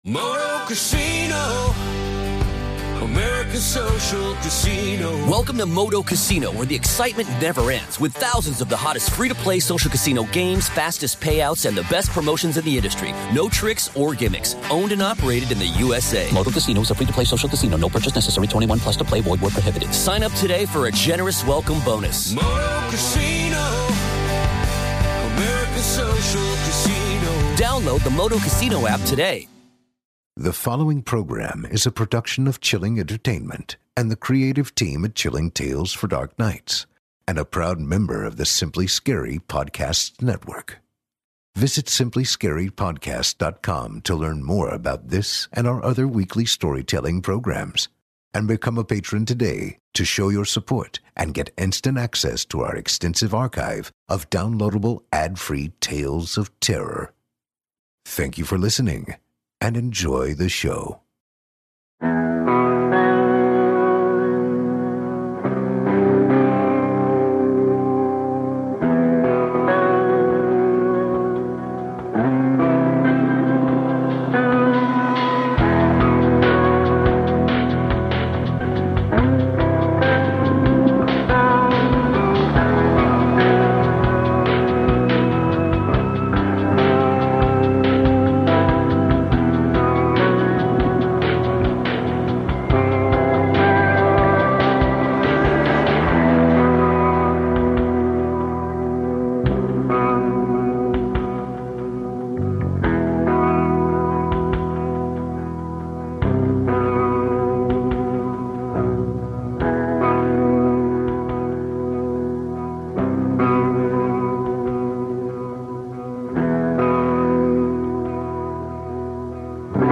As always, I’m your narrator